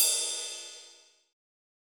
Crashes & Cymbals
OZ-Crash (Orginal).wav